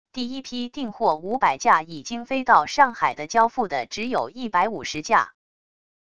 第一批订货五百架已经飞到上海的交付的只有一百五十架wav音频生成系统WAV Audio Player